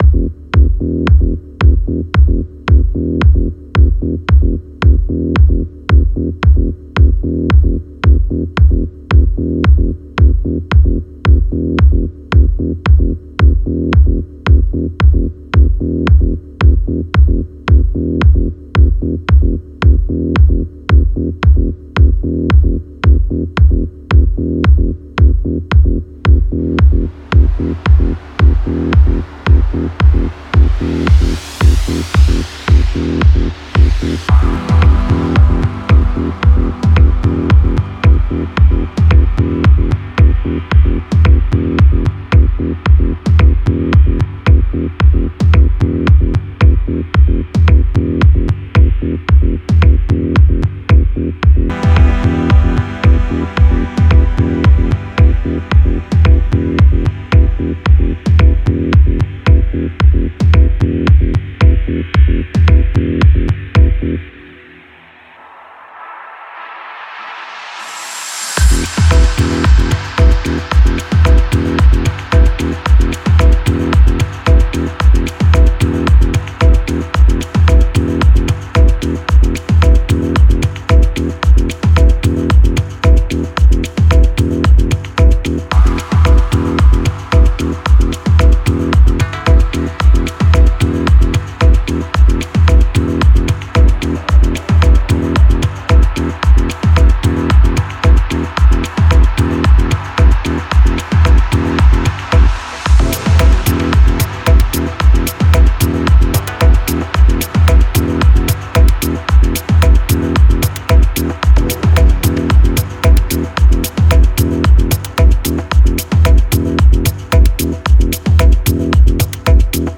Genre: Dub Techno/Ambient/Tech House.